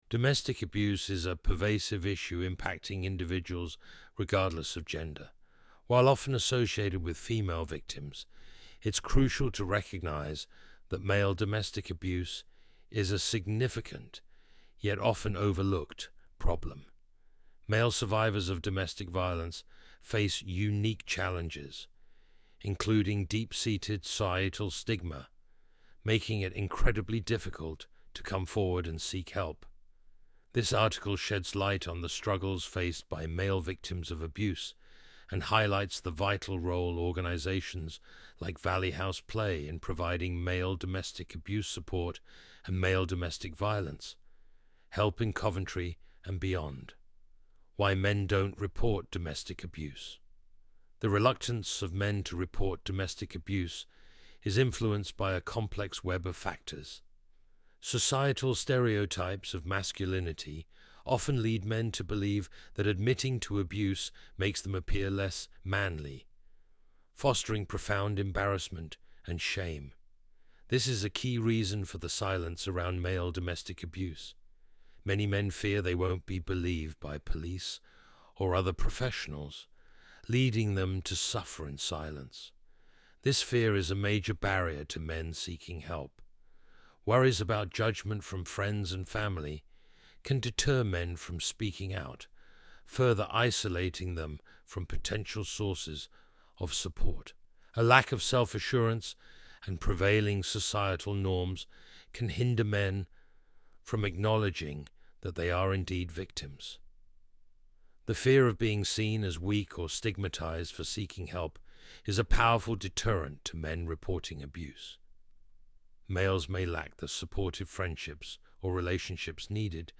VOICEOVER-MALE.mp3